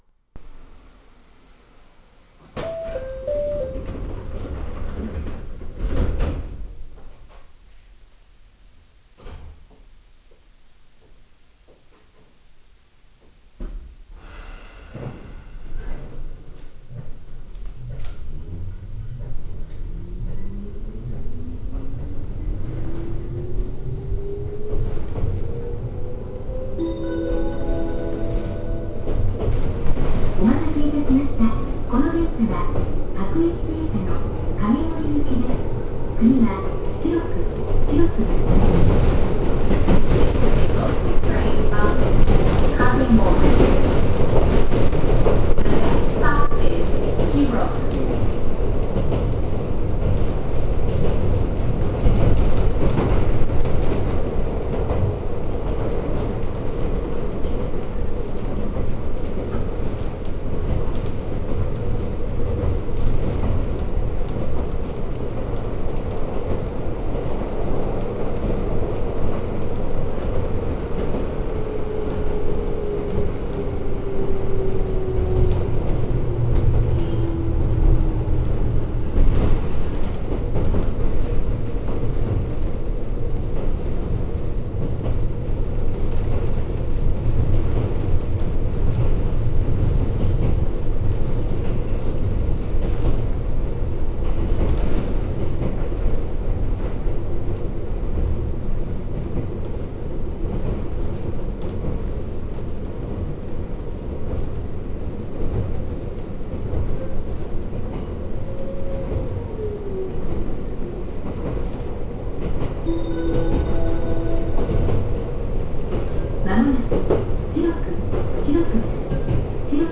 走行装置自体は他の7000系列と変わらないはずなのですが、電装解除により1M車となったため、モーター音の聞こえ方がかなり変わっています。
・7800系走行音
電装解除によって1M車になっているとはいえ、本当に同じモーターを積んでいるのか疑わしくなるほど他の7000系列と音が違います。自動放送やドアチャイムが設置されているのは他の7000系列と同様です。